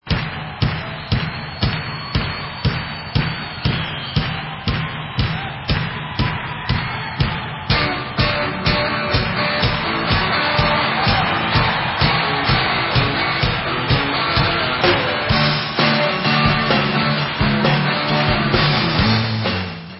Live At Fox Theatre
sledovat novinky v oddělení Southern (jižanský) rock